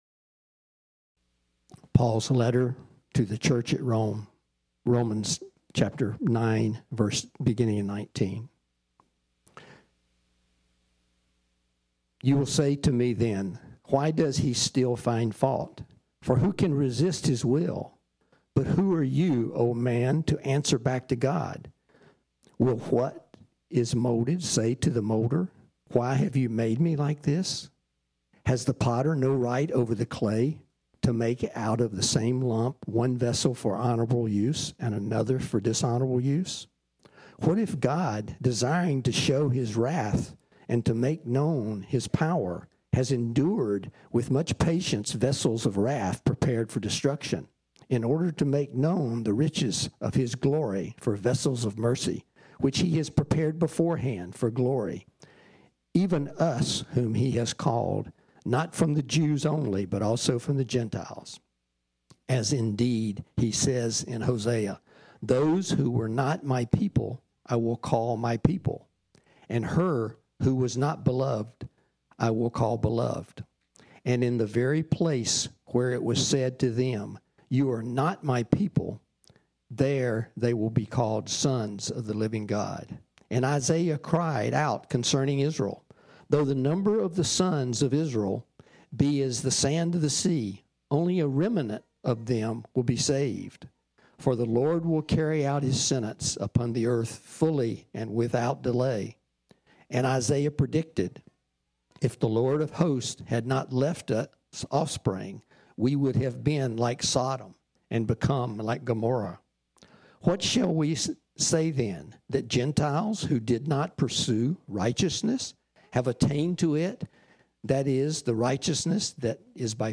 This sermon was originally preached on Sunday, May 30, 2021.